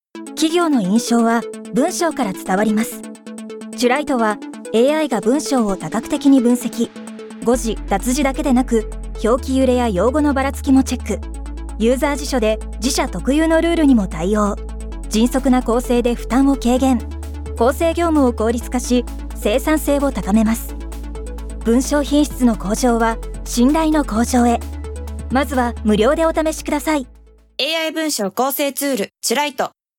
【AI文章校正ツール『ちゅらいと』radikoオーディオアド30秒】 https